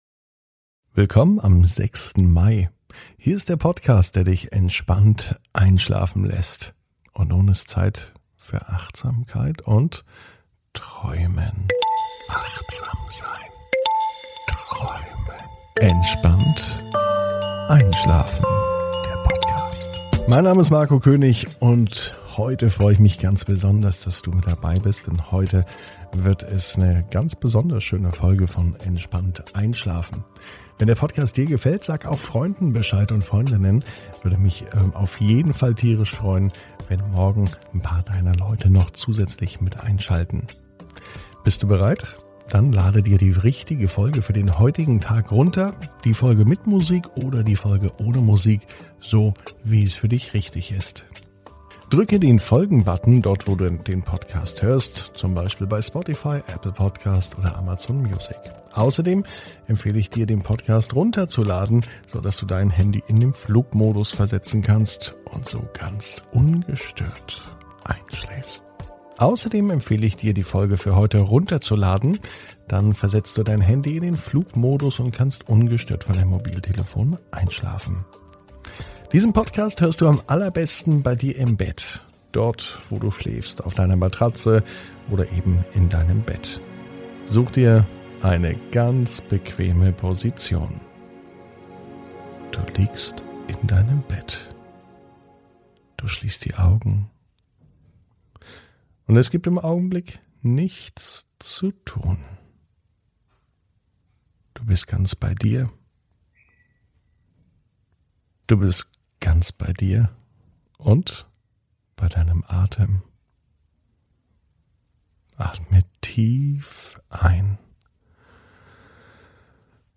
(ohne Musik) Entspannt einschlafen am Donnerstag, 06.05.21 ~ Entspannt einschlafen - Meditation & Achtsamkeit für die Nacht Podcast